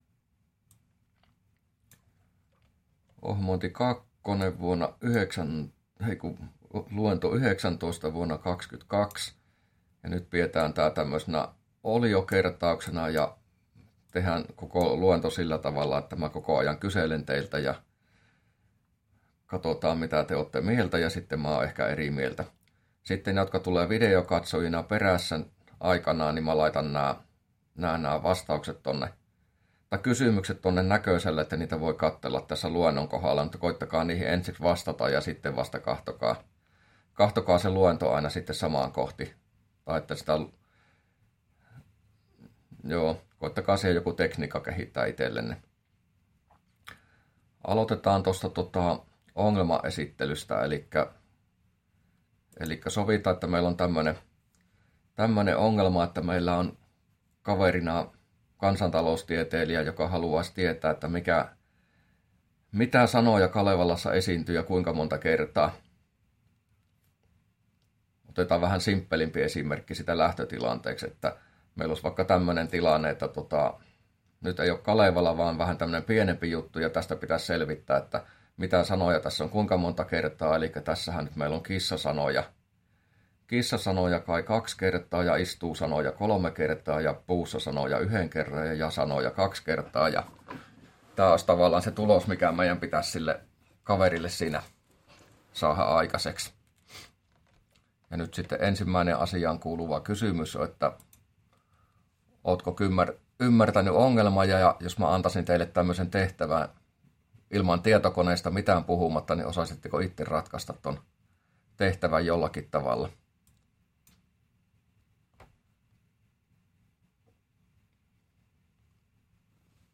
luento19a